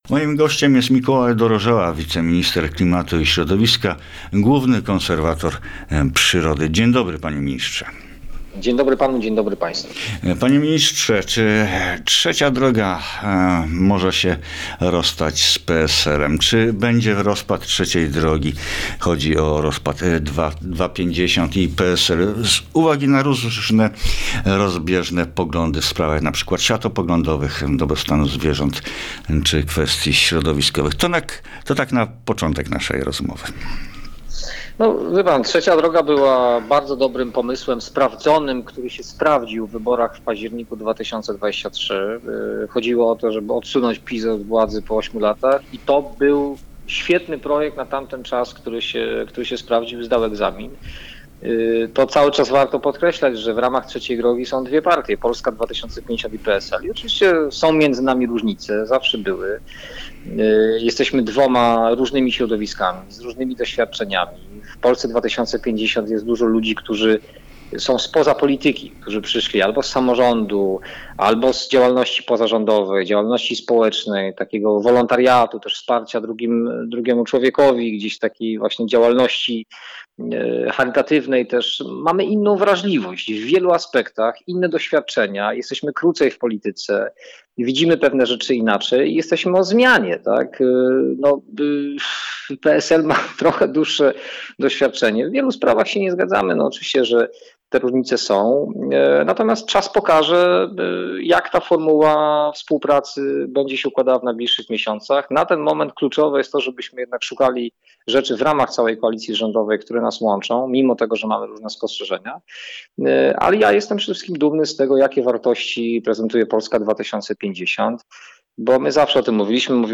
Mikolaj-Dorozala-Rozmowa-Short-2.mp3